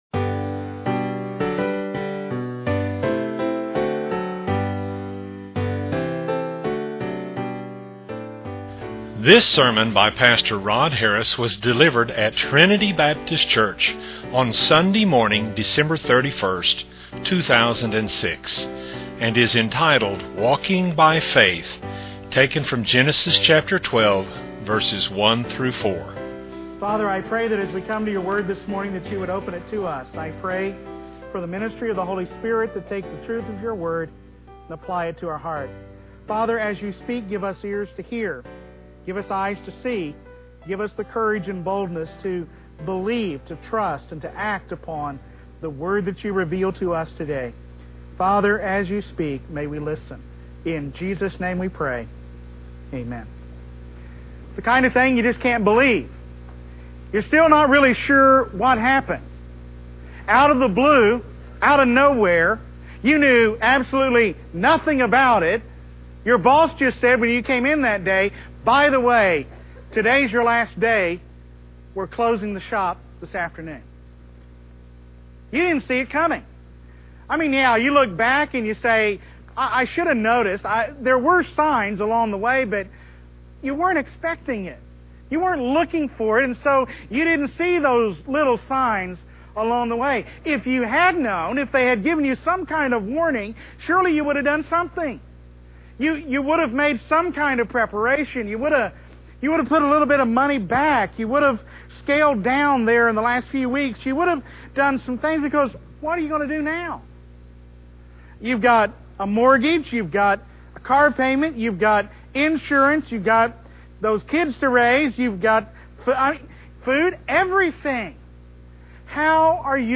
was delivered at Trinity Baptist Church on Sunday morning, December 31, 2006.